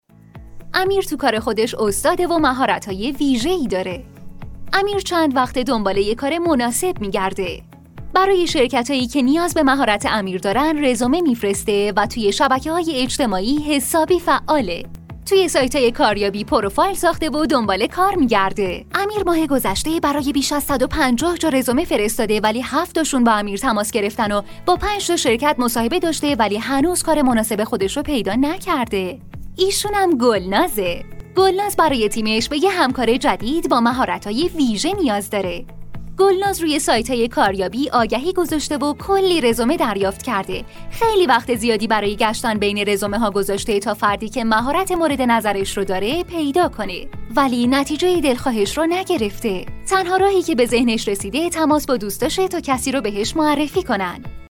Female
Young
Adult